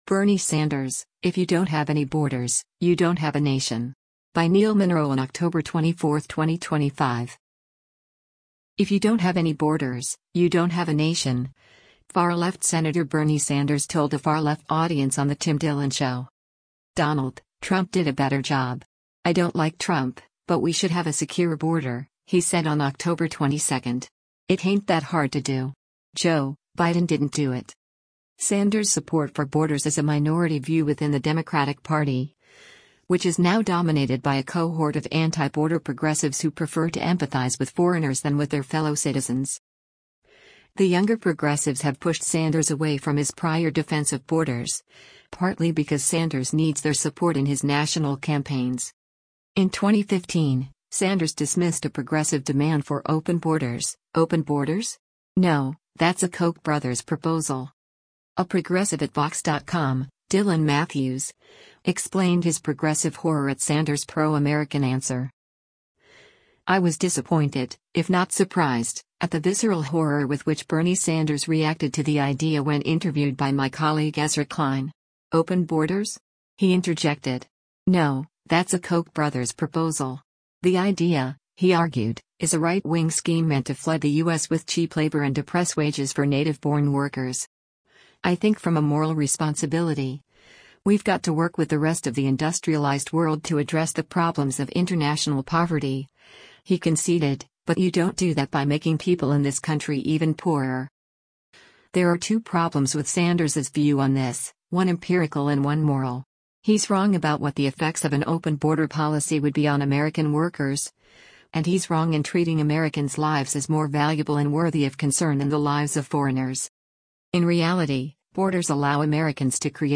“If you don’t have any borders, you don’t have a nation,” far-left Sen. Bernie Sanders told a far-left audience on the Tim Dillon show.